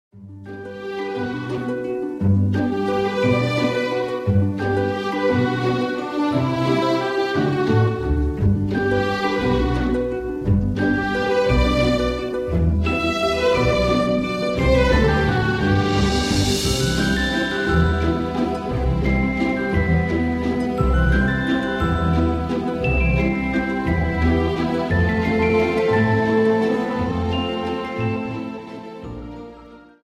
Dance: Viennese Waltz Song